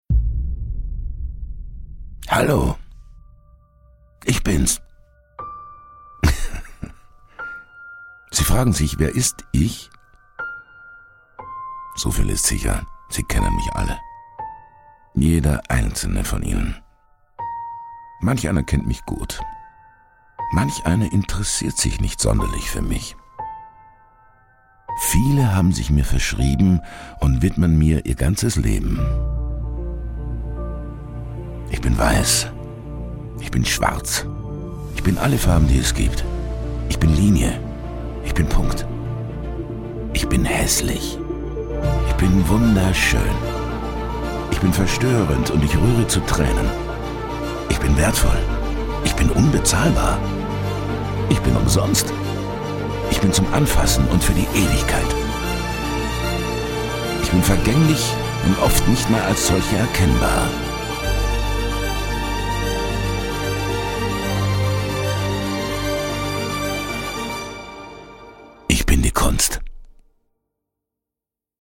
Audio-Ausschnitt aus dem Intro aus Art on Ice 2018: